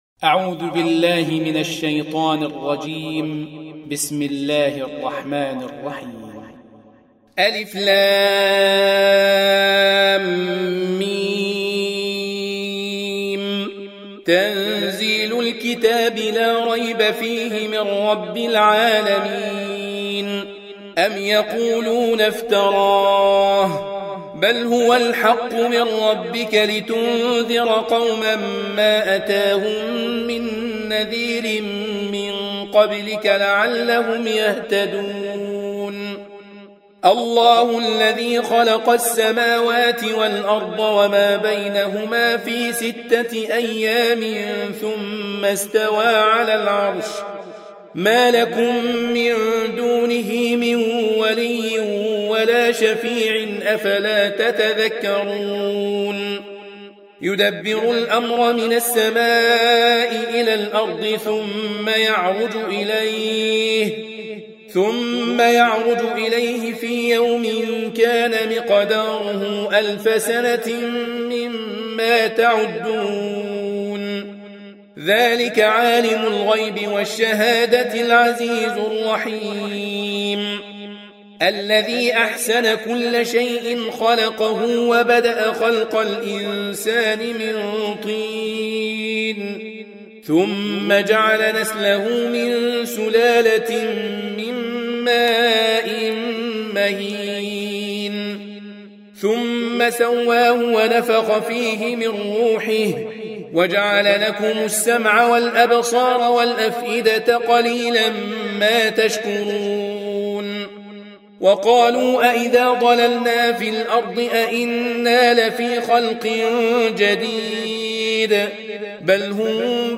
Audio Quran Tarteel Recitation
Surah Repeating تكرار السورة Download Surah حمّل السورة Reciting Murattalah Audio for 32. Surah As�Sajdah سورة السجدة N.B *Surah Includes Al-Basmalah Reciters Sequents تتابع التلاوات Reciters Repeats تكرار التلاوات